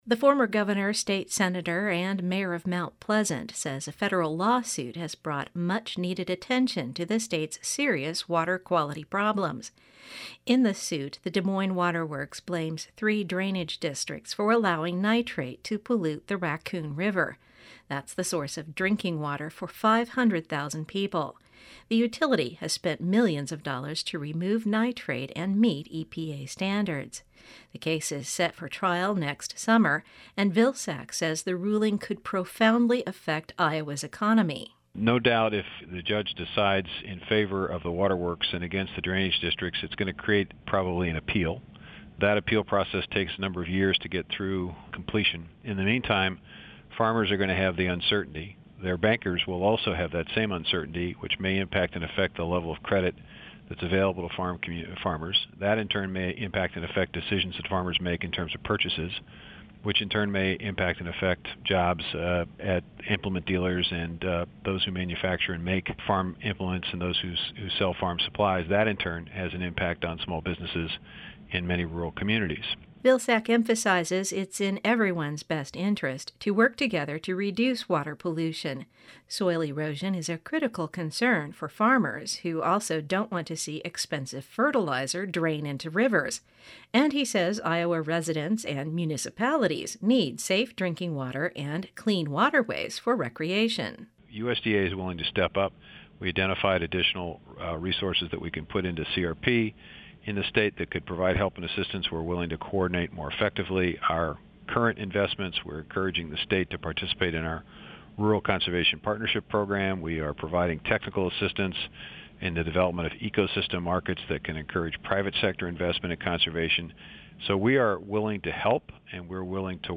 Radio story
FOCUS-VILSACK.mp3